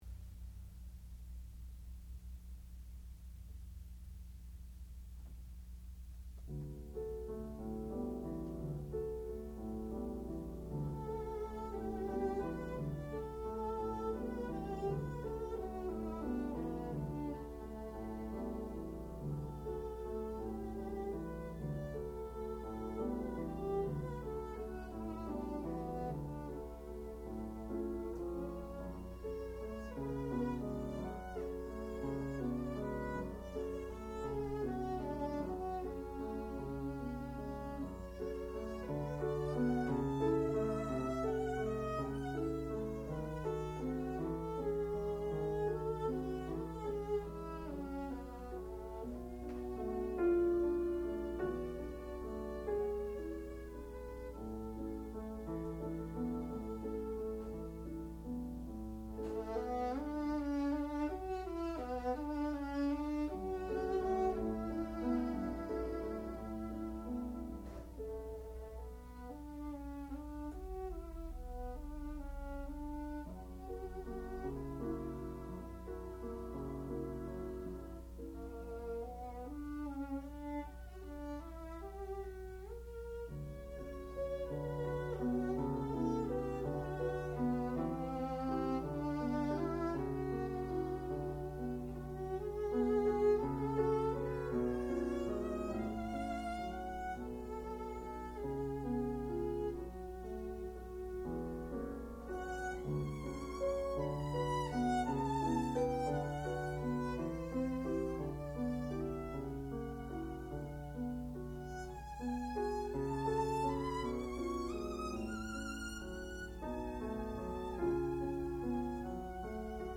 sound recording-musical
classical music
Advanced Recital
violin